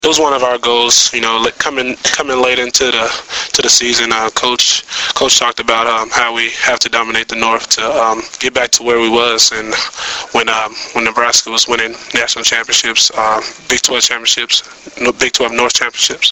Below are links to audio segments of interviews done with Husker players and head coach Bill Callahan after Nebraska's 37-14 win over Colorado.